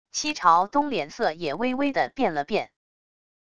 戚朝东脸色也微微地变了变wav音频生成系统WAV Audio Player